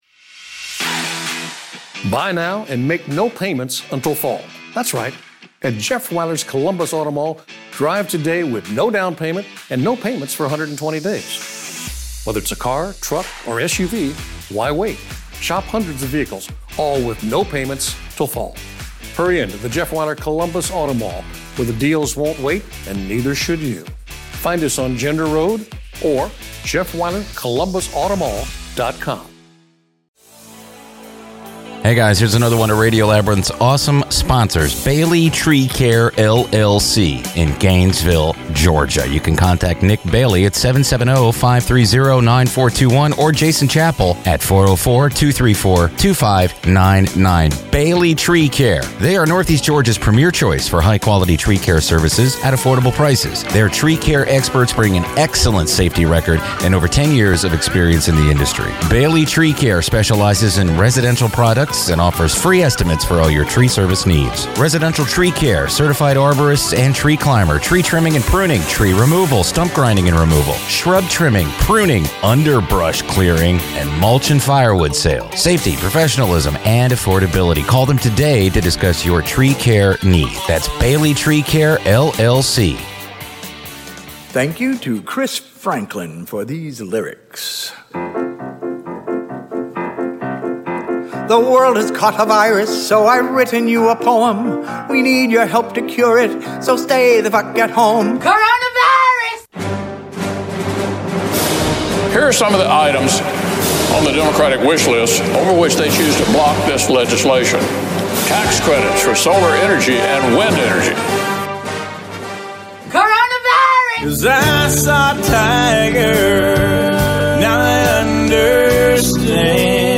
all broadcasting to each other via Skype.